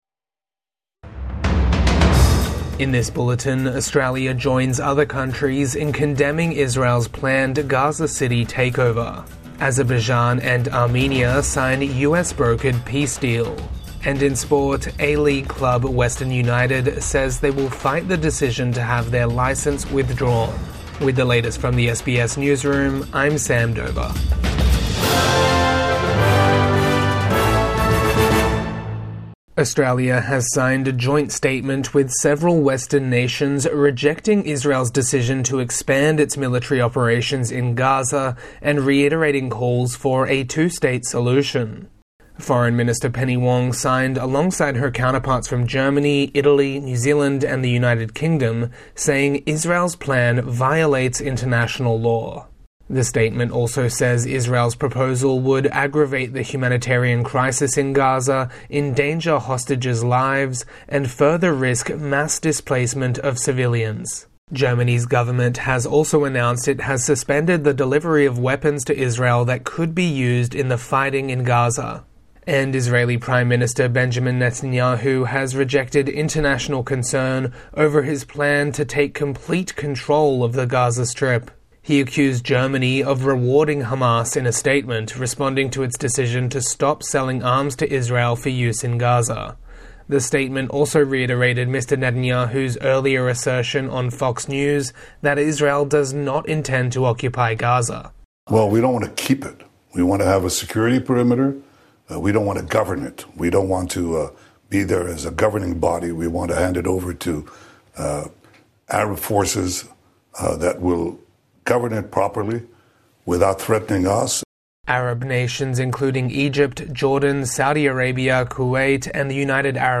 Armenia and Azerbaijan sign peace deal | Midday News Bulletin 9 August 2025